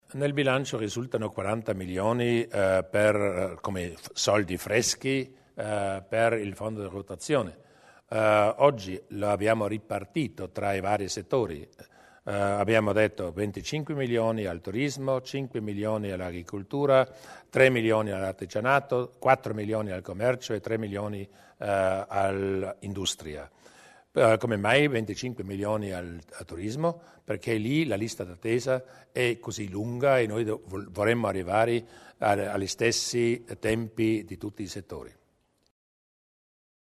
Il Presidente Durnwalder sulla ripartizione del fondo di rotazione